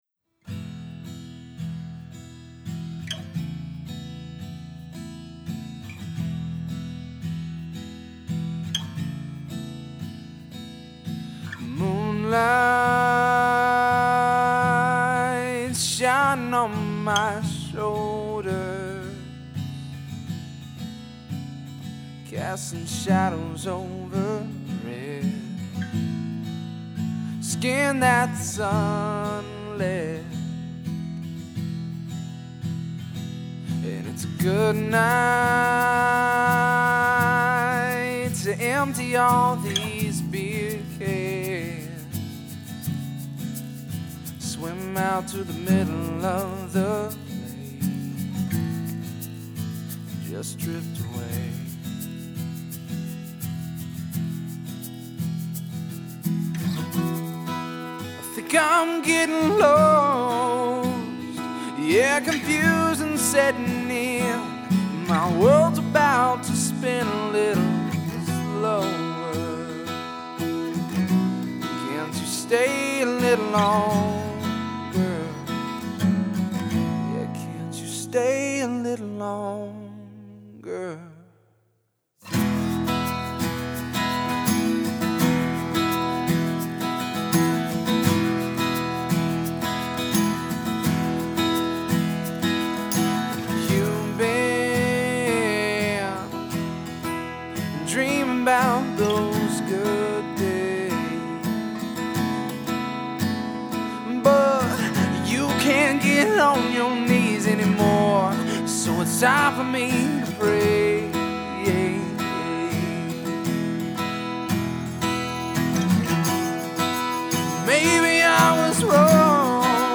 I've been on and off over the past few years, but decided to get back into the action on here This isn't really a 'mix', but I did want to post something that I tracked last night. The vocals have some quick compression and eq, but mostly I just pulled the faders up and panned a few things with some verb.